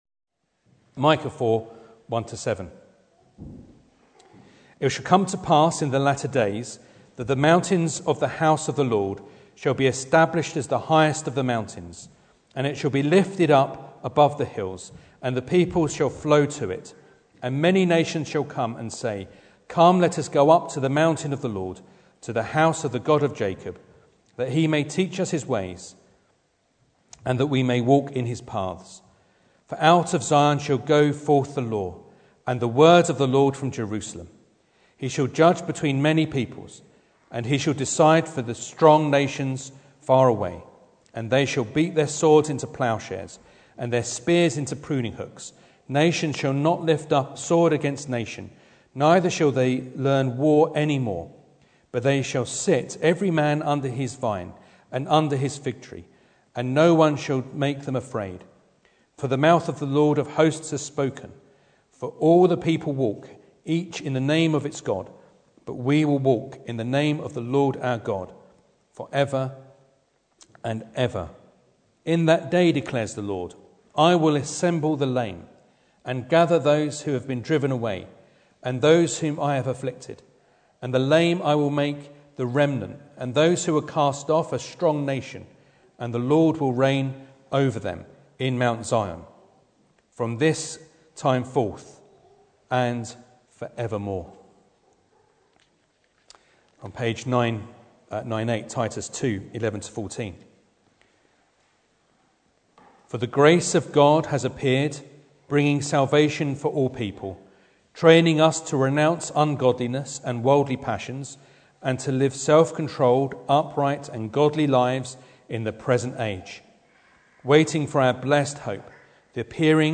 Micah 4:1-7 Service Type: Sunday Morning Bible Text